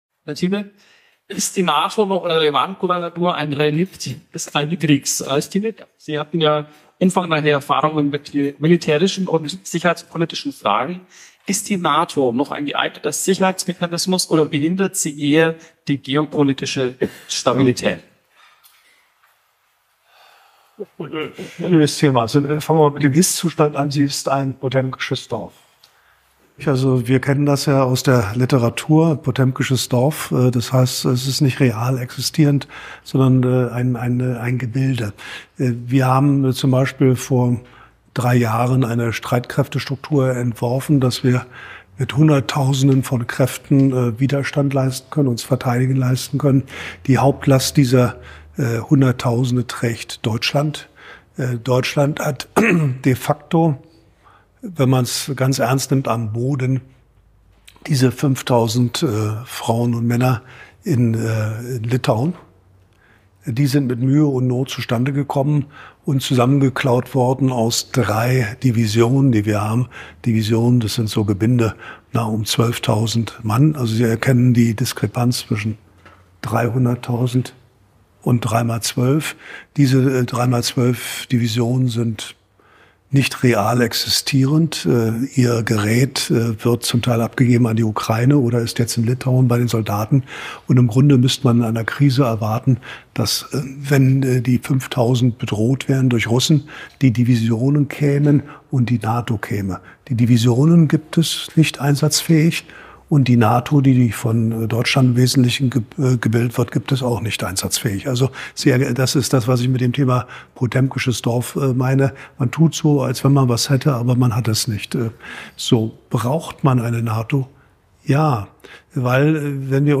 In dieser brisanten Diskussion